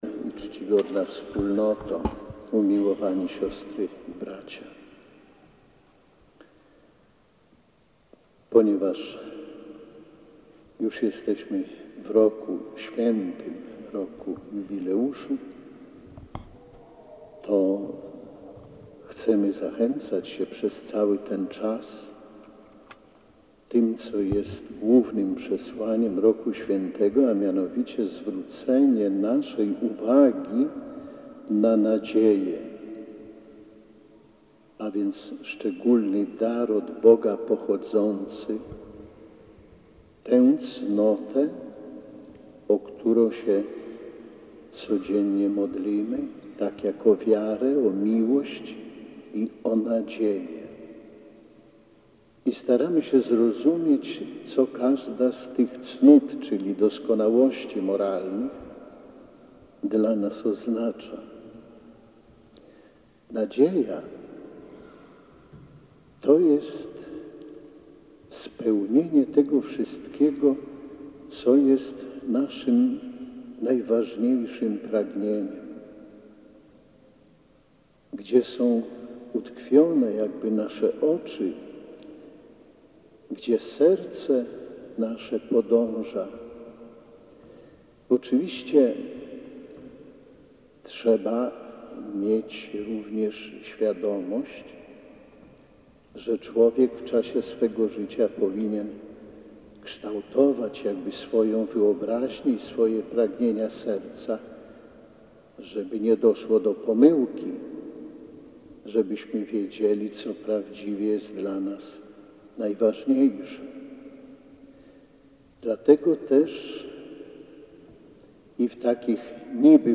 Módlmy się o pokój i nadzieję – apelował biskup warszawsko-praski Romuald Kamiński podczas Mszy świętej na rozpoczęcie nowego roku kalendarzowego w katedrze warszawsko-praskiej.
cala-homilia.mp3